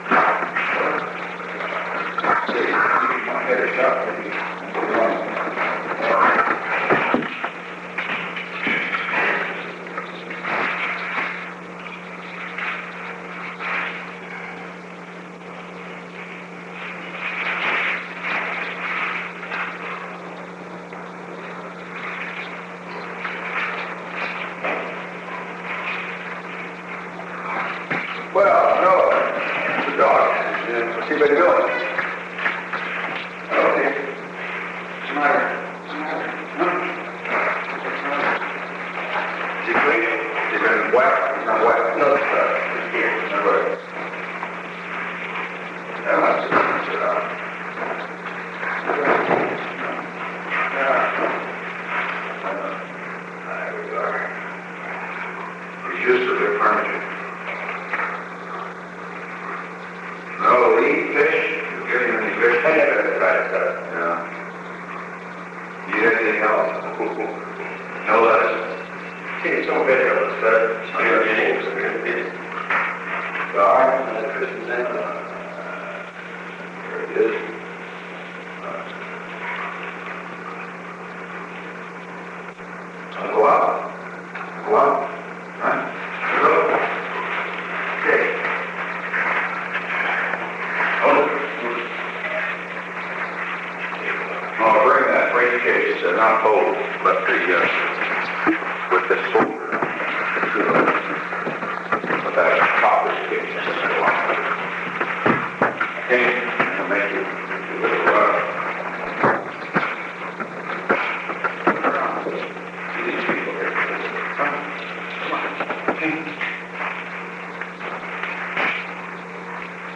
Conversation: 630-024
Recording Device: Oval Office
The Oval Office taping system captured this recording, which is known as Conversation 630-024 of the White House Tapes.